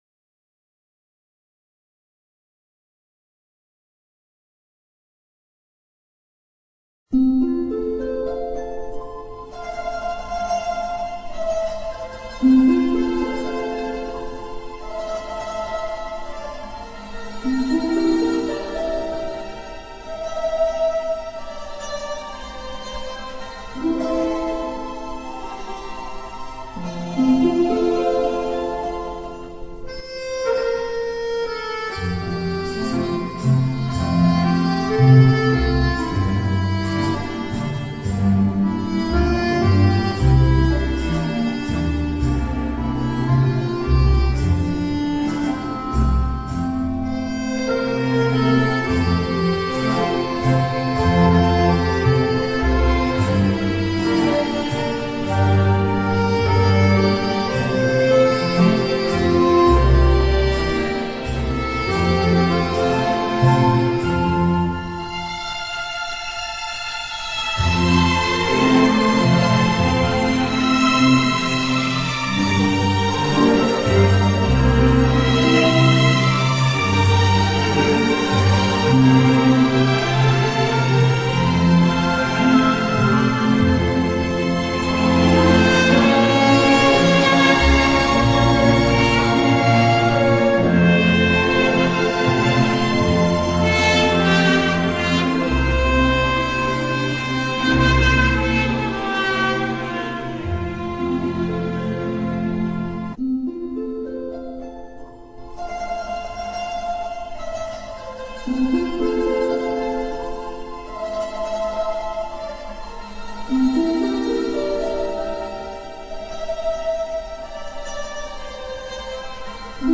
o-sole-mio-karaoke-version-3.mp3